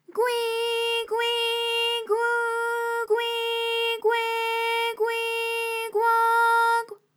ALYS-DB-001-JPN - First Japanese UTAU vocal library of ALYS.
gwi_gwi_gwu_gwi_gwe_gwi_gwo_gw.wav